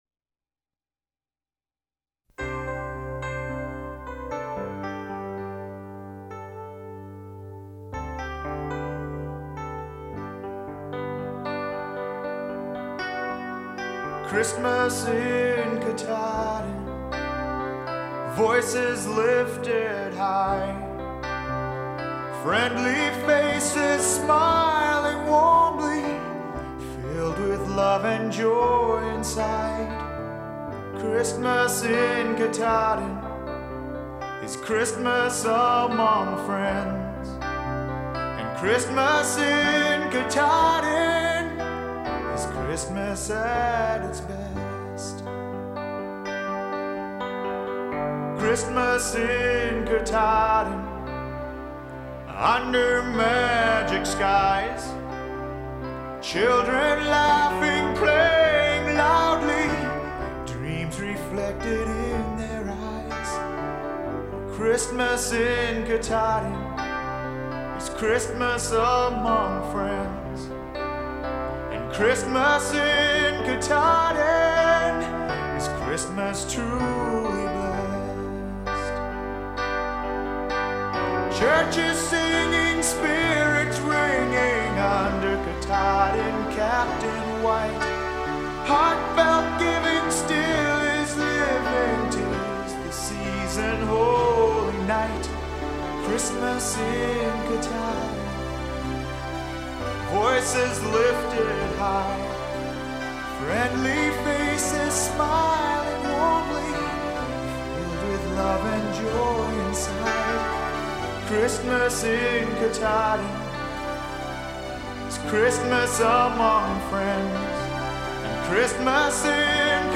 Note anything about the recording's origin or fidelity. (low quality sample, please buy the CD - link below)